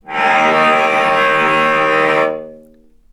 healing-soundscapes/Sound Banks/HSS_OP_Pack/Strings/cello/sul-ponticello/vc_sp-D#2-ff.AIF at a9e67f78423e021ad120367b292ef116f2e4de49
vc_sp-D#2-ff.AIF